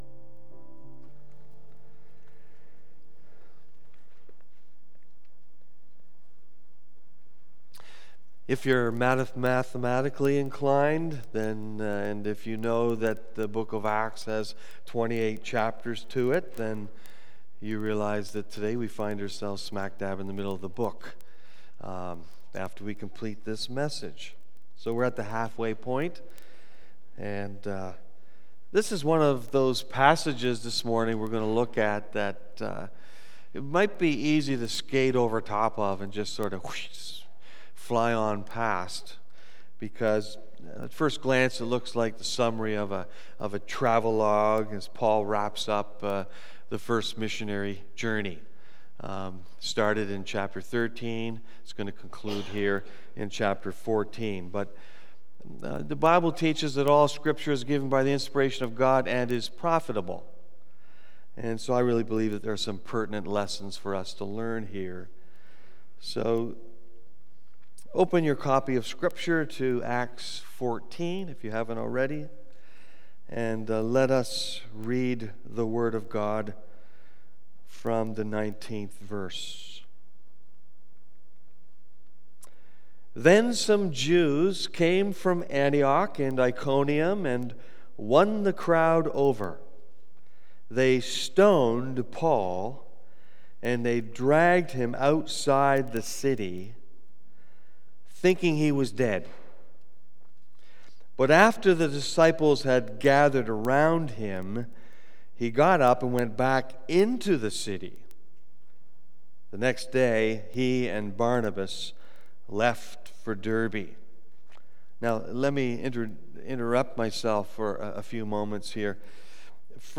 Part 39 BACK TO SERMON LIST Preacher